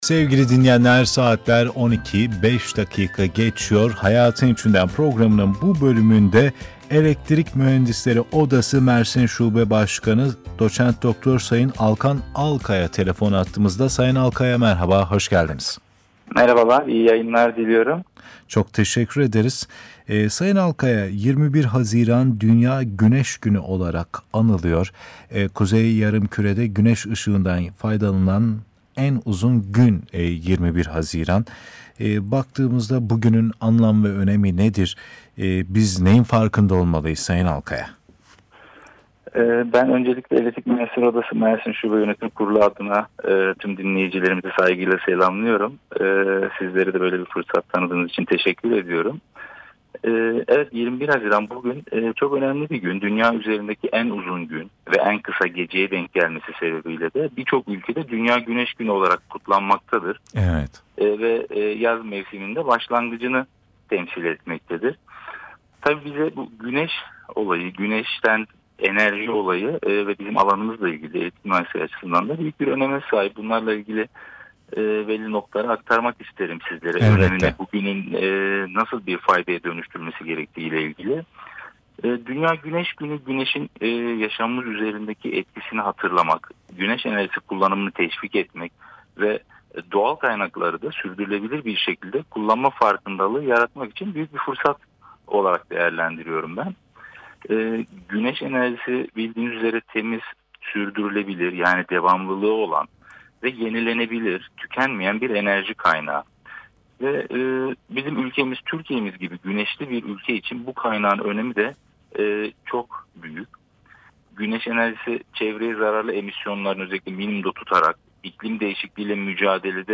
RADYO YAYINININ KAYDINI EKL� DOSYADAN D�NLEYEB�L�RS�N�Z.